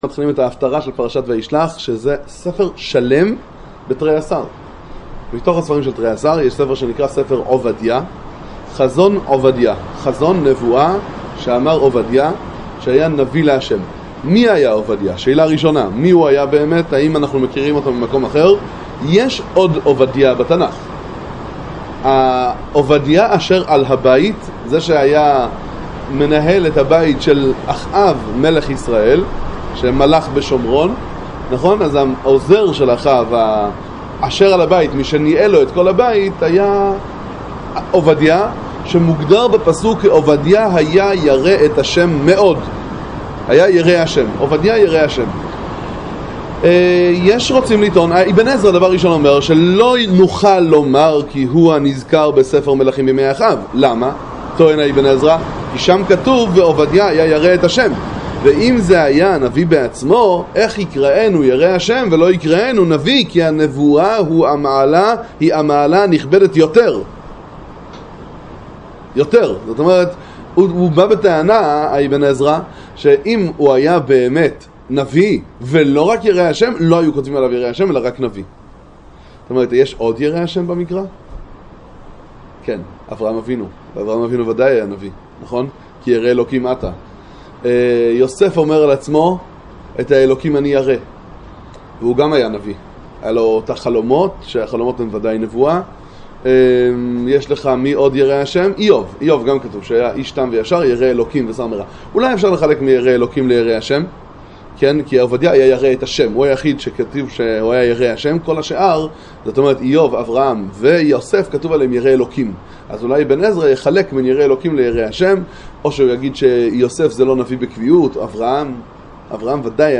שיעורי תורה לצפיה על נביאים וכתובים, הפטרות פרשת השבוע